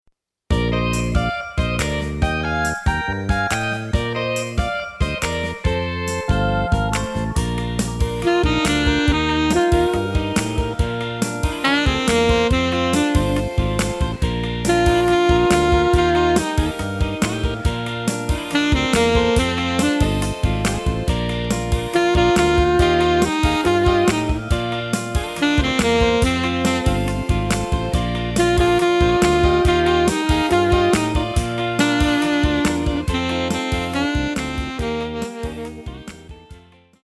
Saxophone Alto